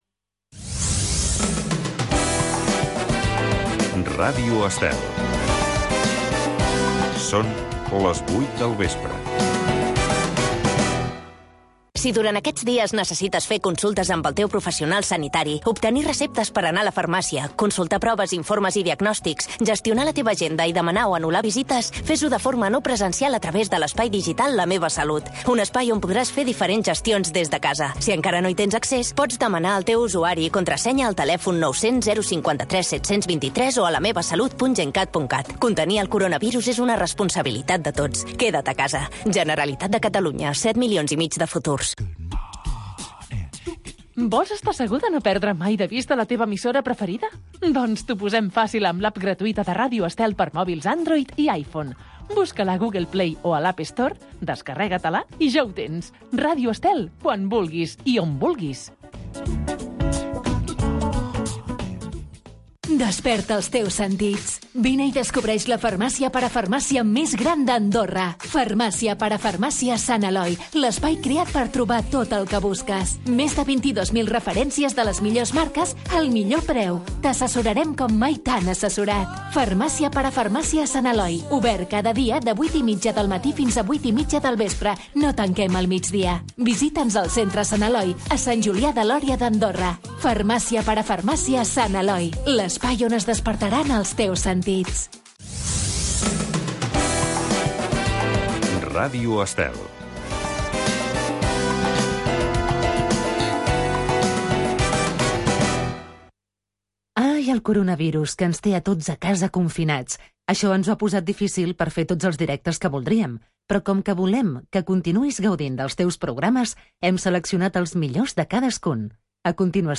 El Protagonista. Entrevista en profunditat a un personatge destacable del món de la política, societat, religió i cultura.